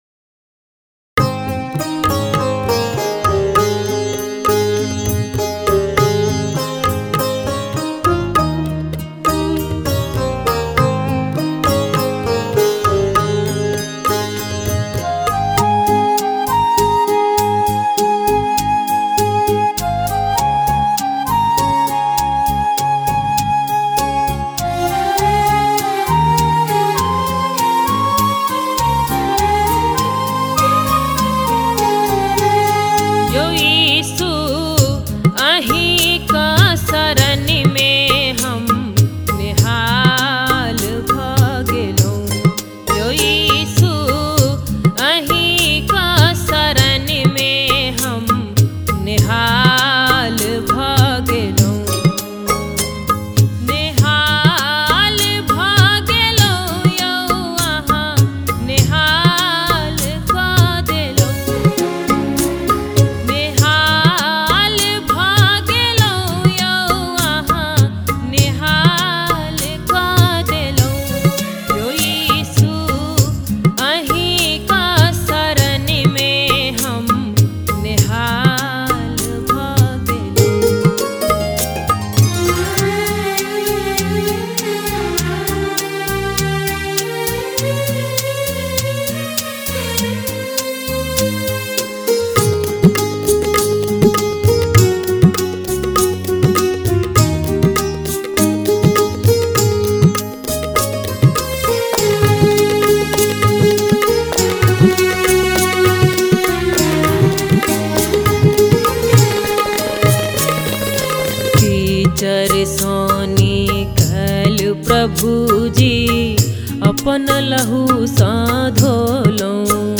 Royalty free Christian music.